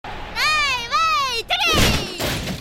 drop kick gold ship uma musume Meme Sound Effect
drop kick gold ship uma musume.mp3